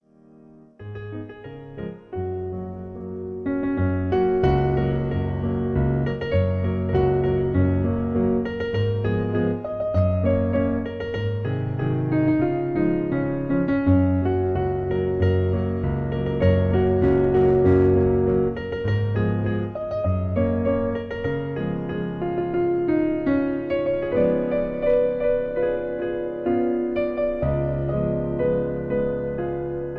Lied aus der Operette